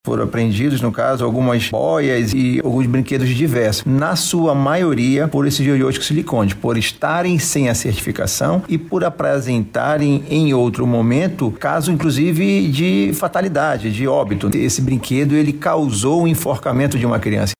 O diretor-presidente do Ipem Amazonas, Renato Marinho, destacou alguns produtos que apresentaram irregularidades.